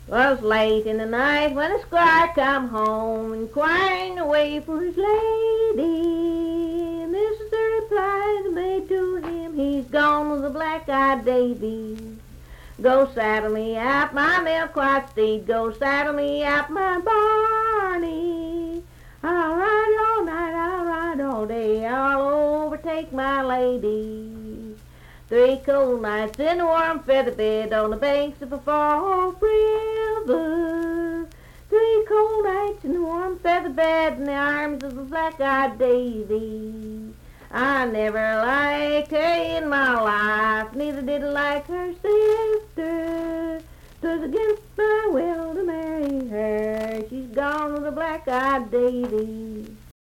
Unaccompanied vocal music
Voice (sung)
Richwood (W. Va.), Nicholas County (W. Va.)